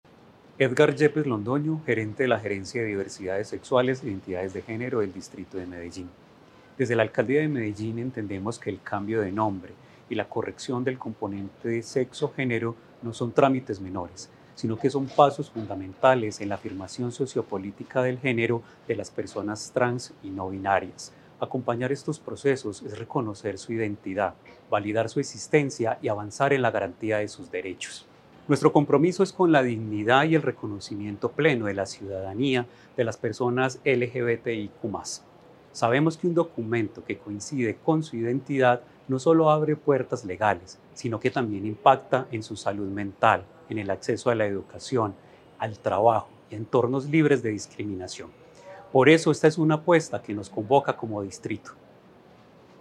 Palabras de Edgar Yepes Londoño, gerente de Diversidades Sexuales e Identidades de Género de Medellín